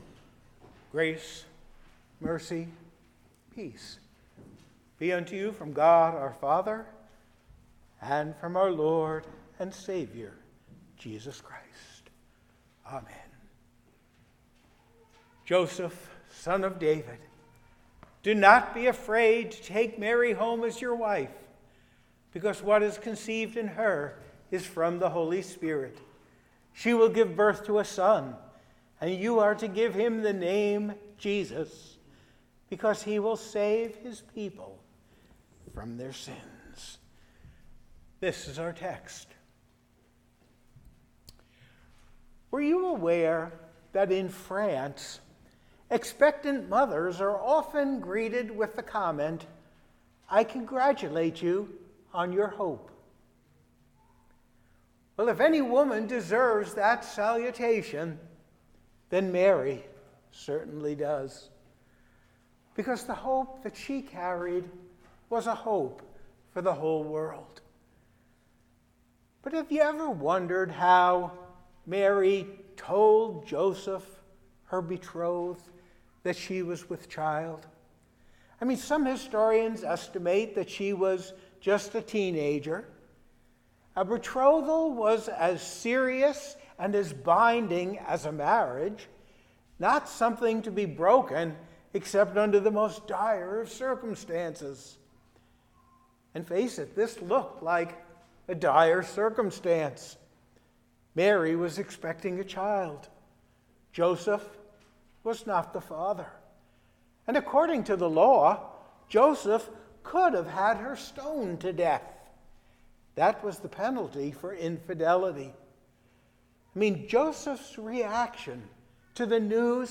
Listen to the recorded sermon and service from Zion Lutheran Church.
Sermon_Dec21.mp3